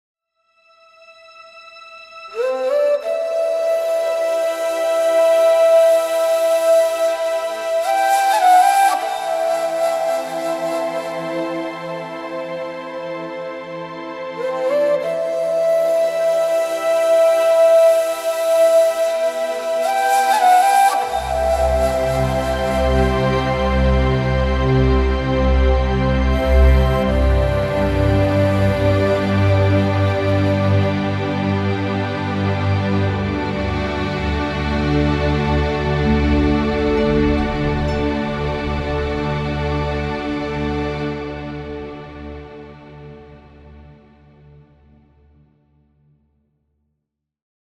• Качество: 320, Stereo
спокойные
без слов
инструментальные
Флейта
Красивая инструментальаня мелодия